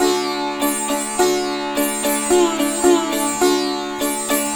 105-SITAR3-R.wav